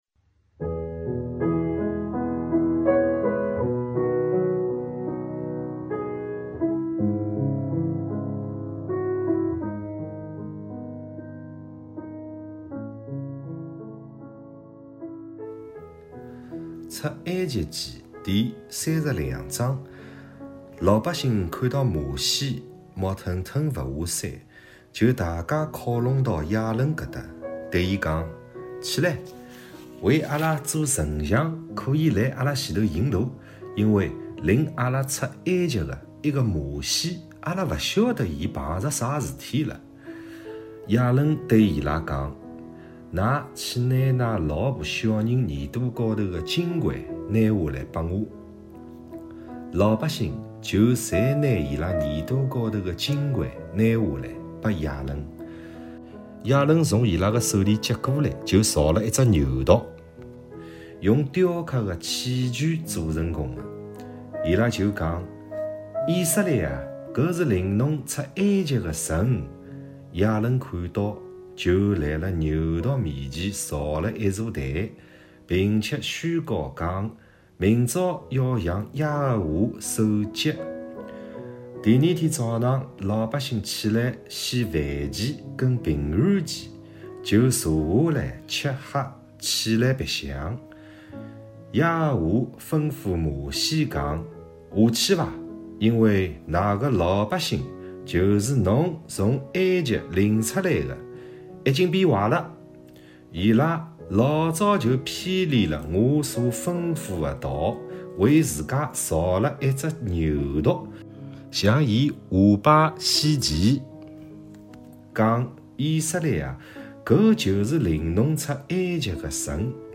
语言：上海话